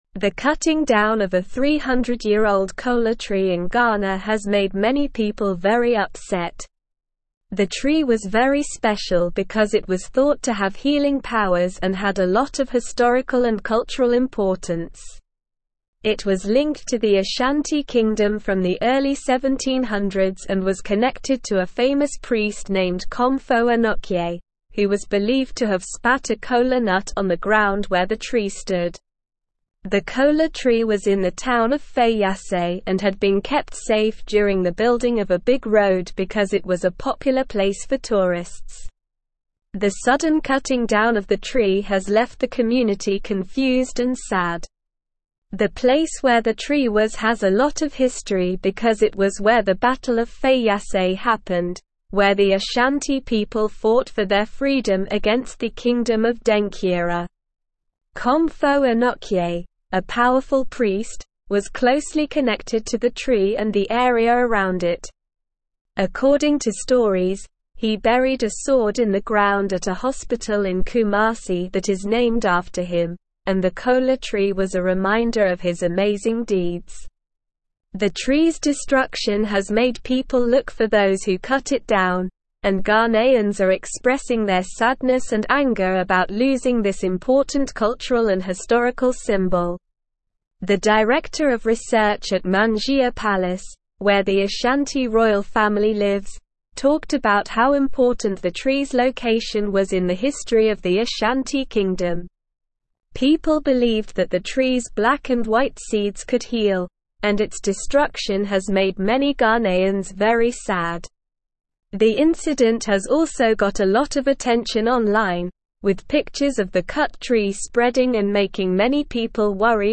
Slow
English-Newsroom-Upper-Intermediate-SLOW-Reading-Manhunt-in-Ghana-for-Culprits-Behind-Ancient-Trees-Destruction.mp3